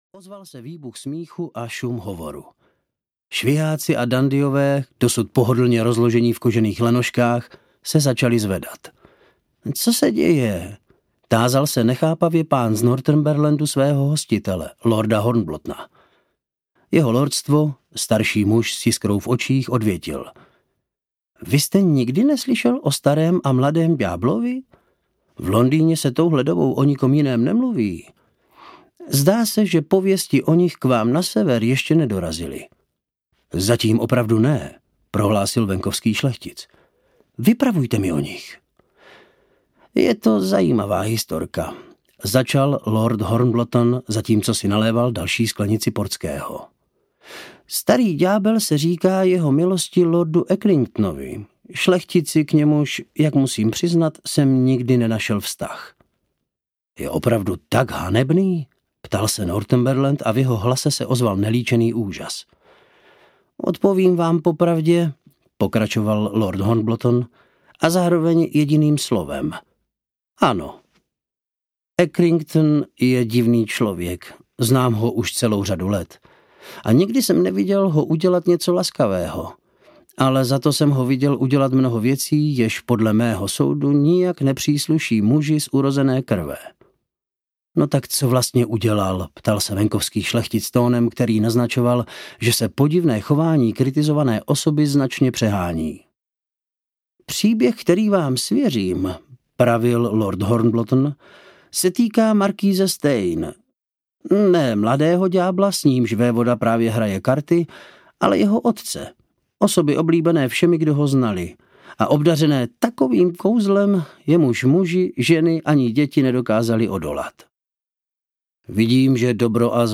Šťastná Fortuna audiokniha
Ukázka z knihy